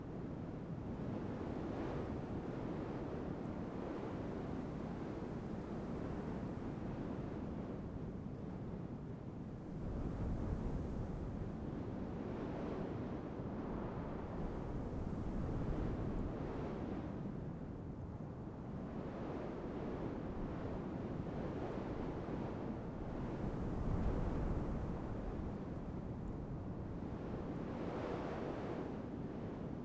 Wind.wav